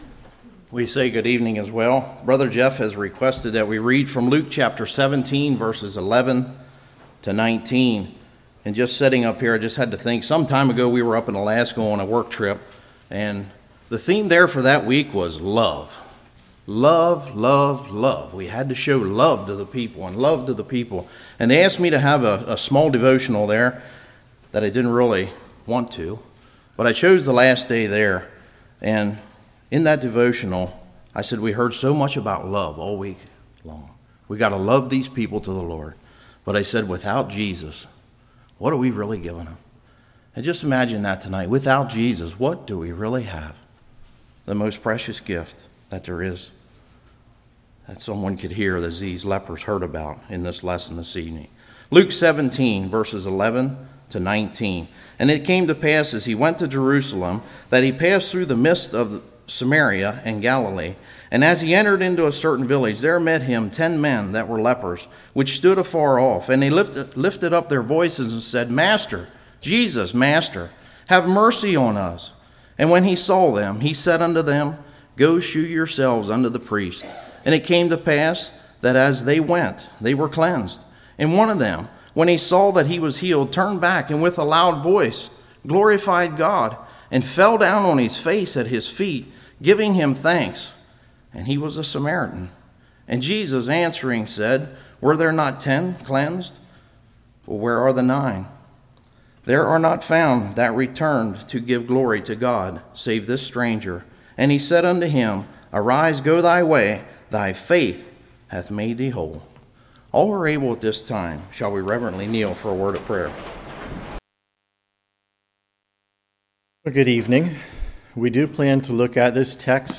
Luke 17:11-19 Service Type: Evening Where are in this account of the 10 lepers?